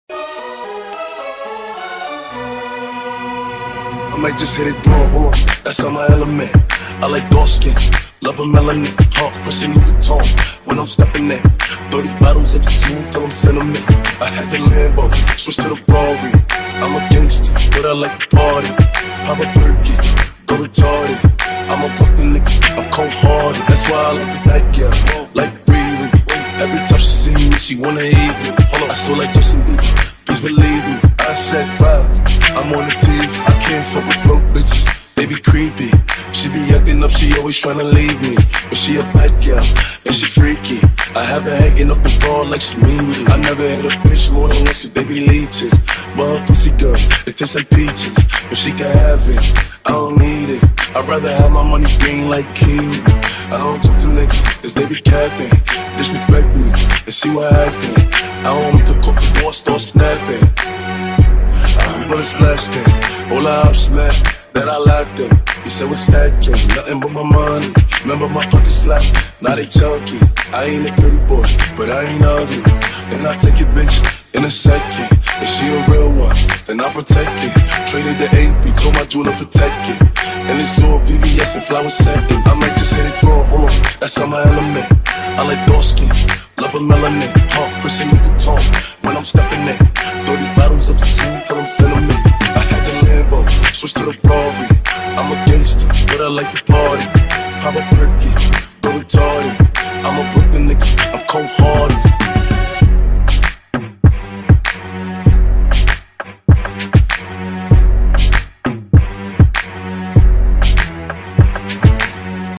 объединяющая элементы хип-хопа и трэпа.